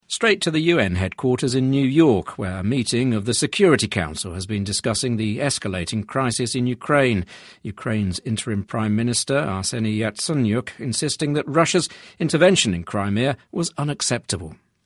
【英音模仿秀】乌克兰临时总理现身说法 听力文件下载—在线英语听力室